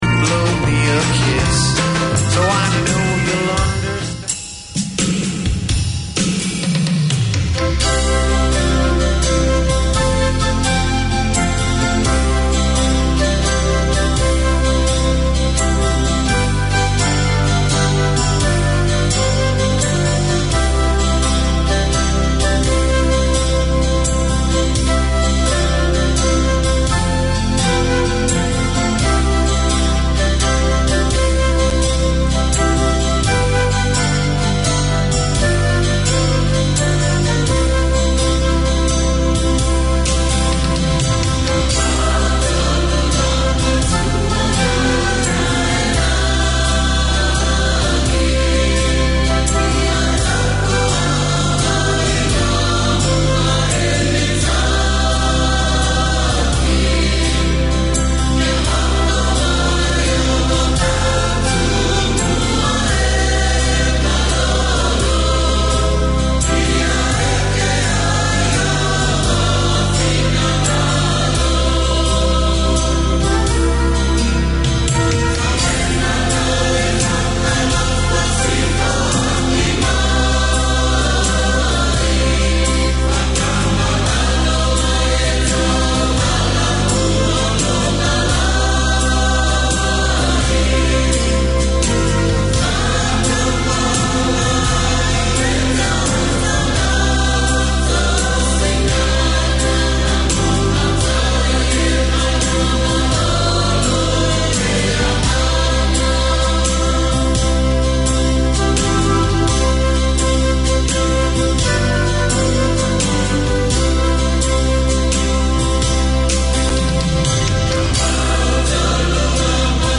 Tune in for singing, devotions and great music.